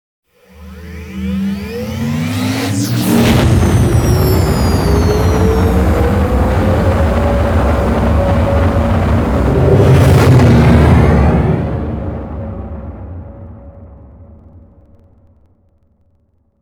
OtherLaunch1.wav